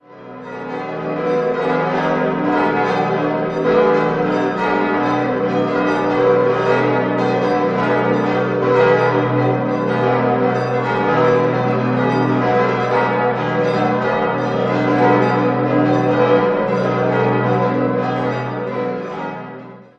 8-stimmiges Geläute: h°-dis'-fis'-gis'-ais'-h'-d''-a''